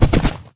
gallop2.ogg